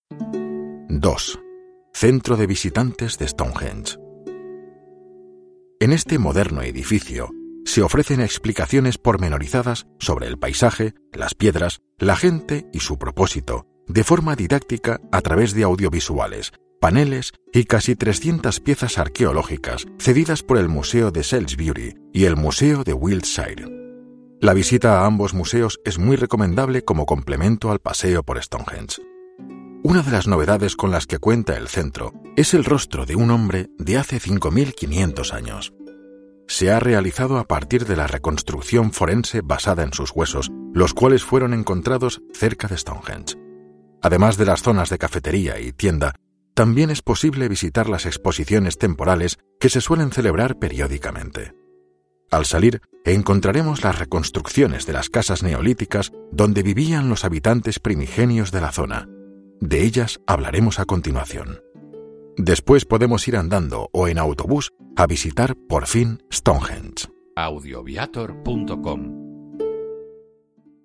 audioguía_Stonehenge_Reino_Unido_ES_02.mp3